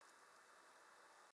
silence.wav